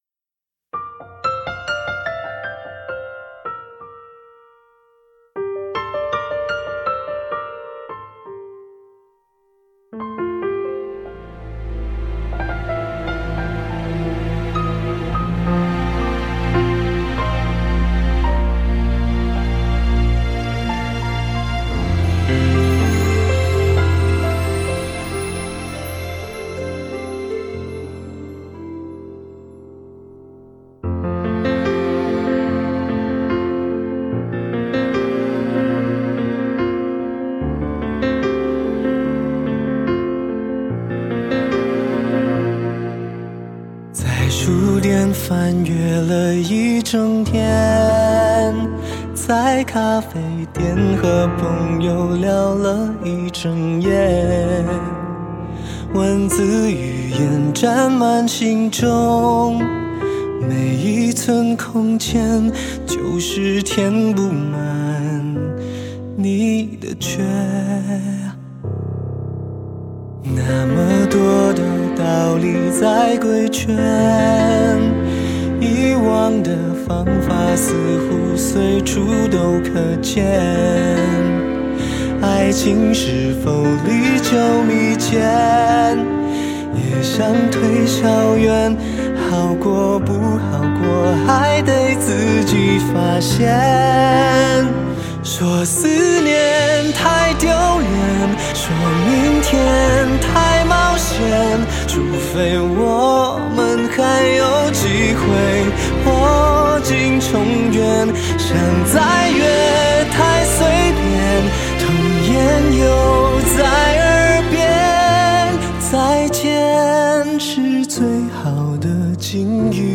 回归音乐本质